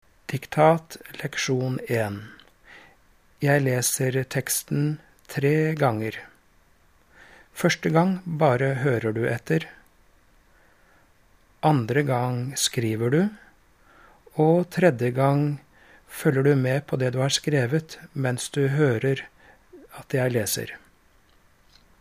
Diktat
Instruksjon